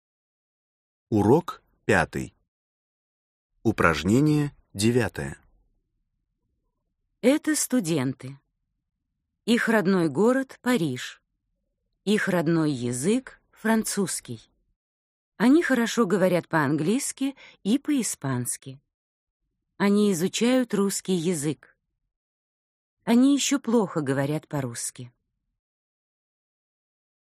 Аудиокнига Жили-были… 28 уроков русского языка для начинающих. Рабочая тетрадь | Библиотека аудиокниг